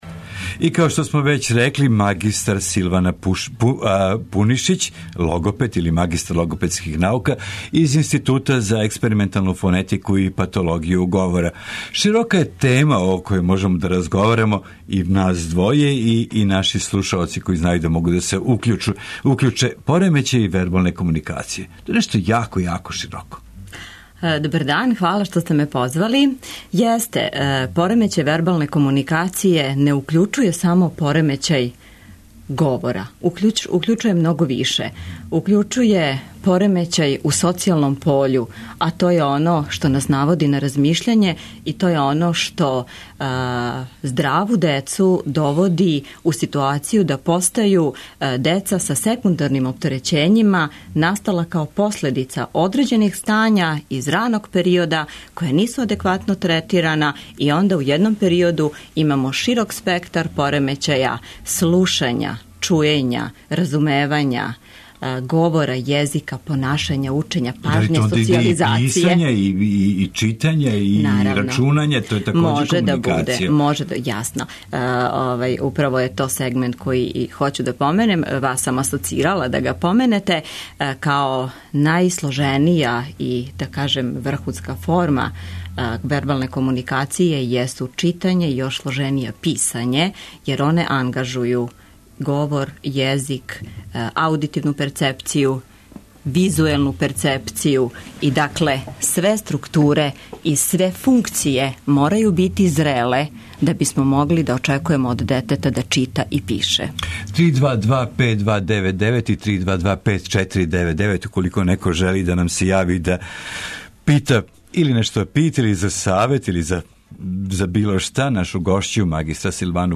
Једно од питања које ћемо јој поставити је зашто нека деца не проговоре ни са три године. Гошћа емисије ће ,наравно, радо одговaрaти и на ваша питања.